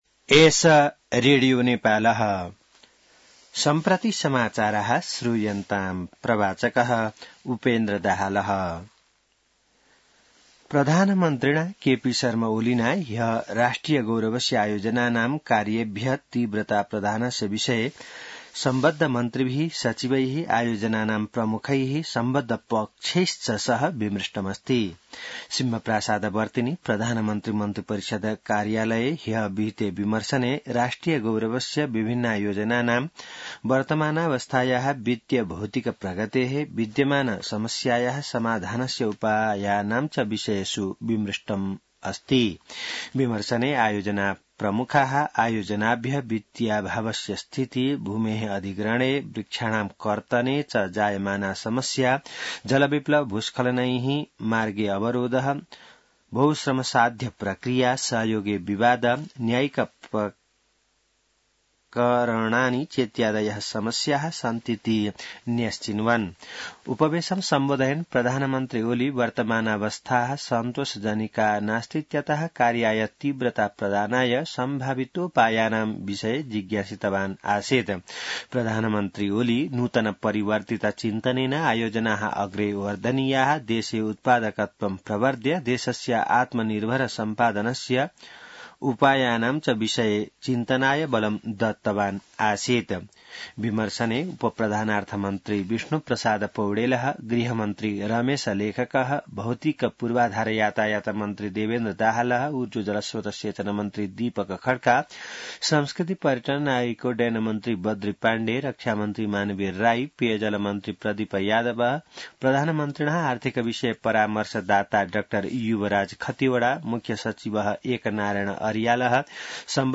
संस्कृत समाचार : ११ मंसिर , २०८१